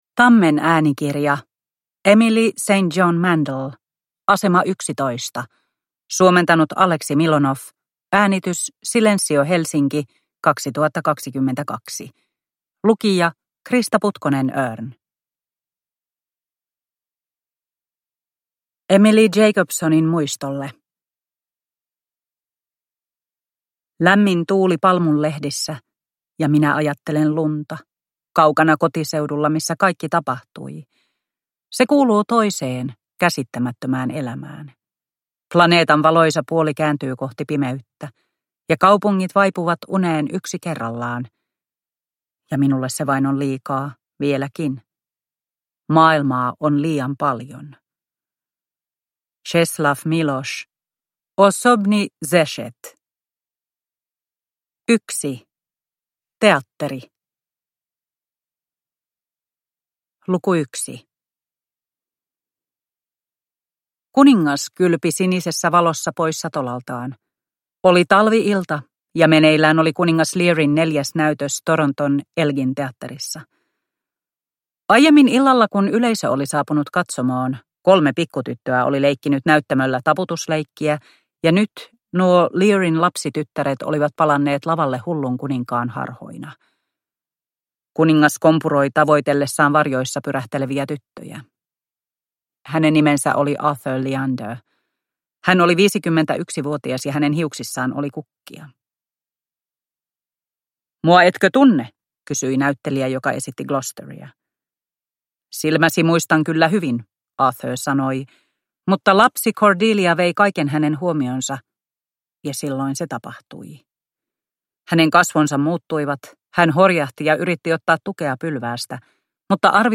Asema 11 – Ljudbok – Laddas ner